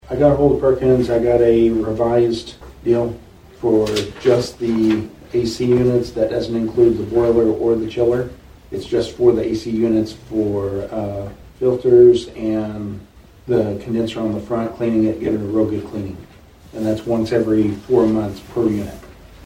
The Nowata County Commissioners met for a regularly scheduled meeting on Monday morning at the Nowata County Annex.
Chairman Paul Crupper discussed a revised quote for air conditioning units.